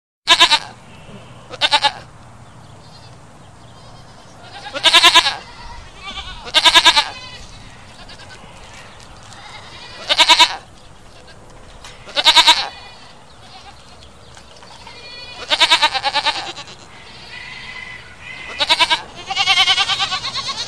Звук - Блеяние козы (Goat)
Отличного качества, без посторонних шумов.